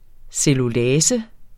Udtale [ sεluˈlæːsə ]